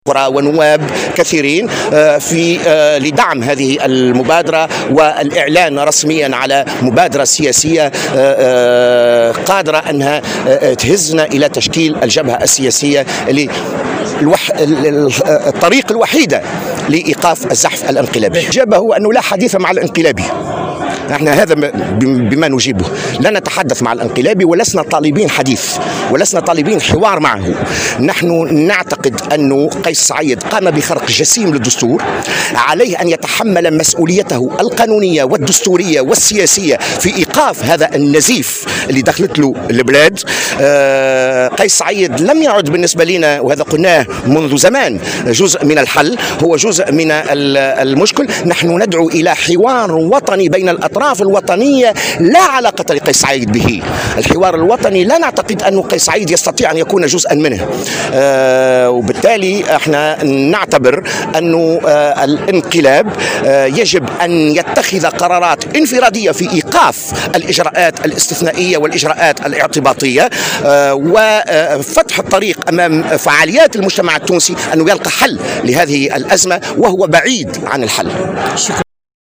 قال الناشط السياسي و منسق حملة "مواطنون ضدّ الانقلاب"، جوهر بن مبارك، في تصريح لمراسل الجوهرة اف أم، إنه سيتم اليوم الإعلان عن مبادرة سياسية وطنية، بمشاركة عديد الشخصيات السياسية والنواب، وذلك من أجل تشكيل جبهة سياسية موحدة قادرة على إيقاف ما اسماه "الزحف الانقلابي".
ودعا بن مبارك، لدى مشاركته في وقفة إحتجاجية لحراك "مواطنون ضد الإنقلاب" انتظمت اليوم الأحد، أمام المسرح البلدي بالعاصمة، إلى عقد حوار وطني يجمع كافة الأطراف السياسية "الوطنية"، ويُستثنى منه قيس سعيد، لإيجاد حل للأزمة التي تمر بها البلاد.